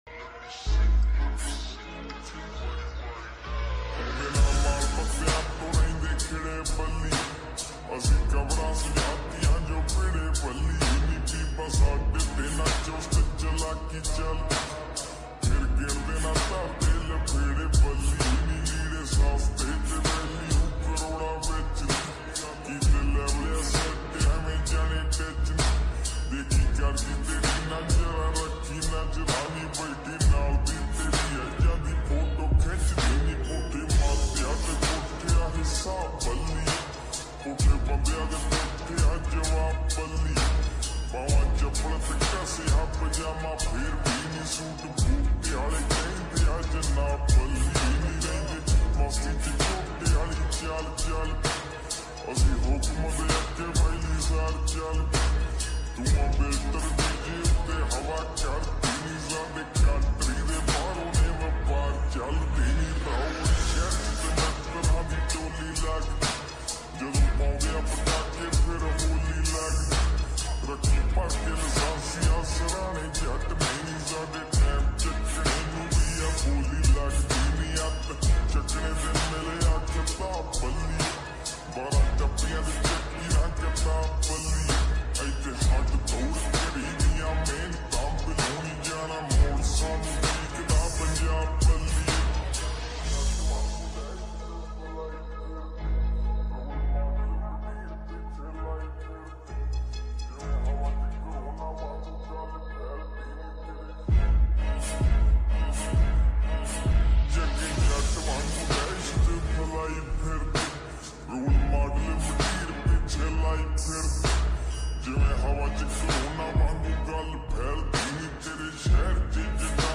PUNJABI ULTRA HD SLOWED SONG